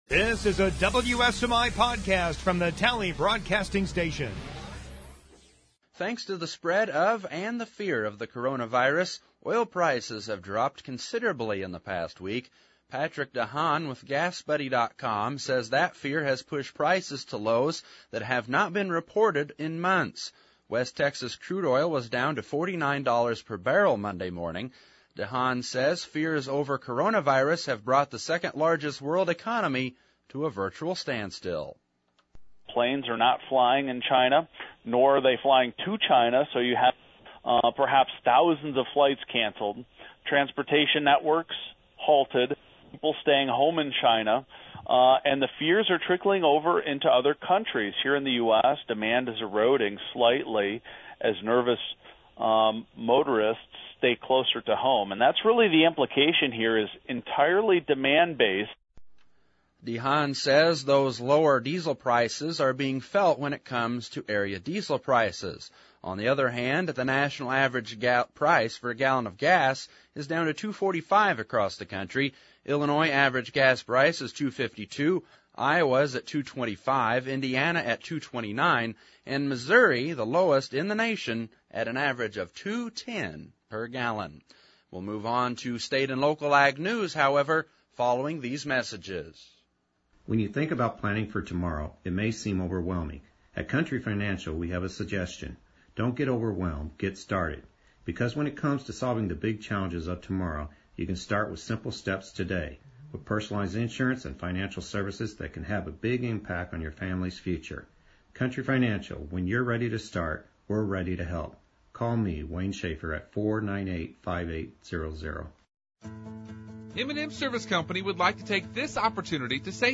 2. Interview w/